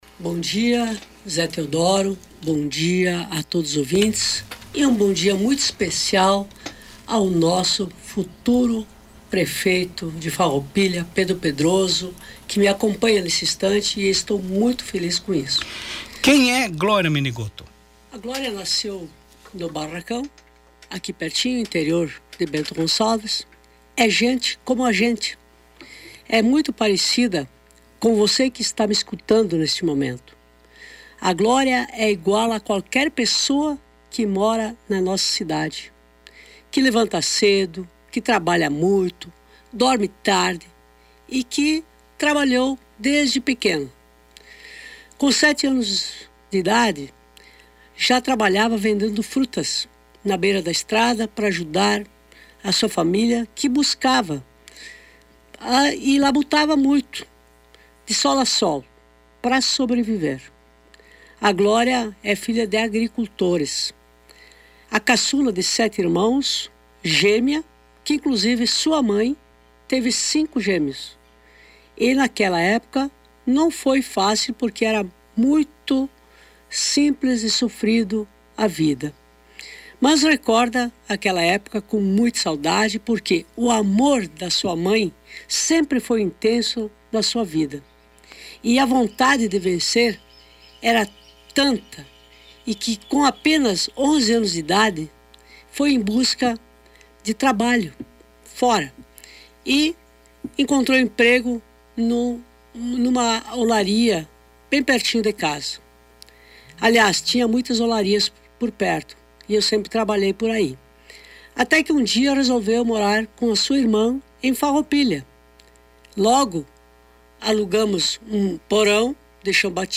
Áudio da entrevista: